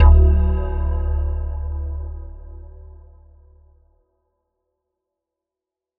Bass_D_03.wav